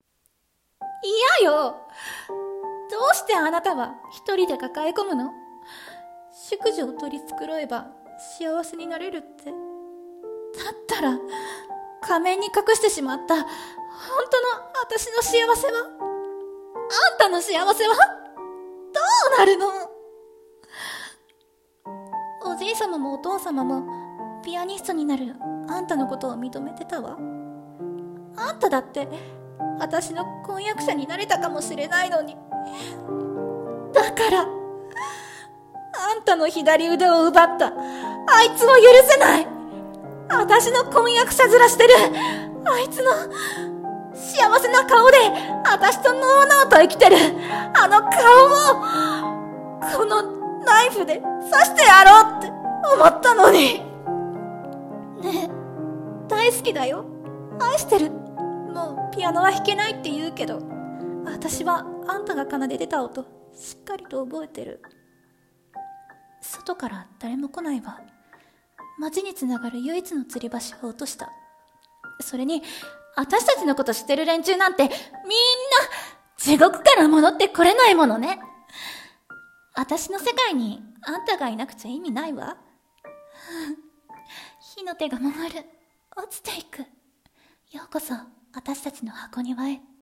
声劇「ようこそ箱庭へ」女性視点